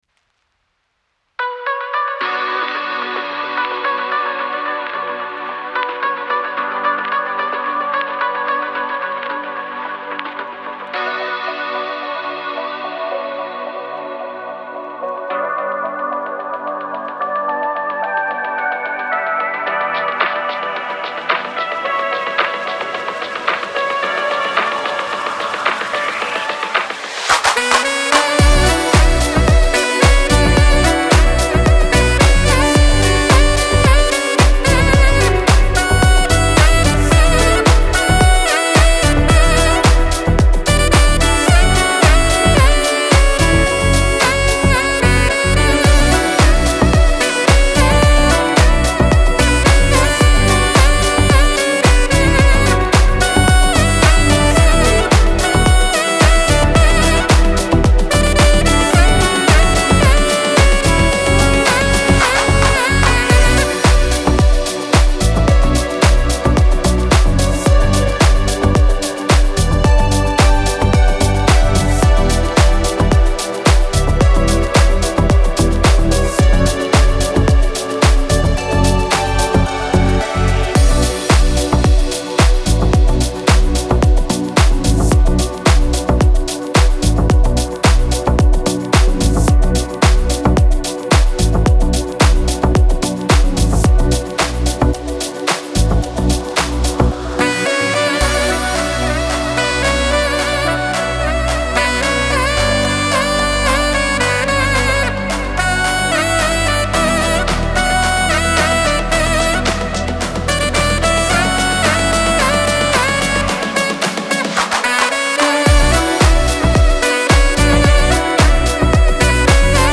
موسیقی کنار تو
دیپ هاوس
موسیقی بی کلام ساکسیفون شاد